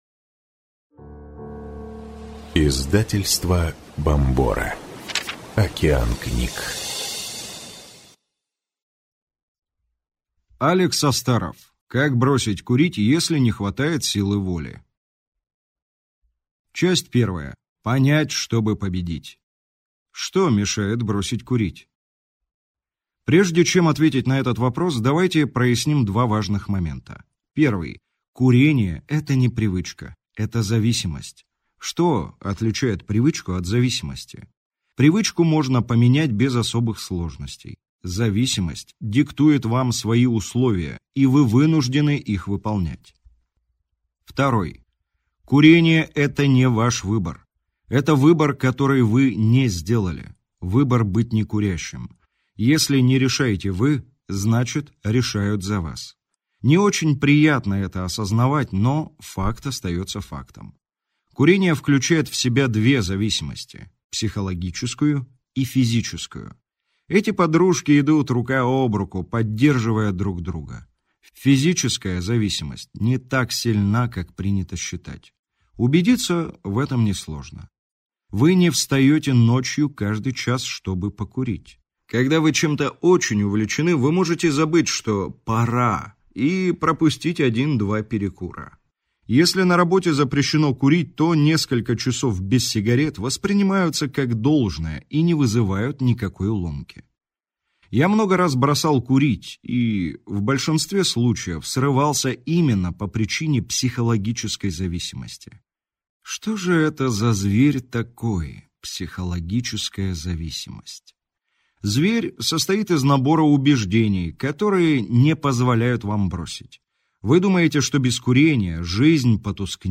Аудиокнига Как бросить курить, если не хватает силы воли | Библиотека аудиокниг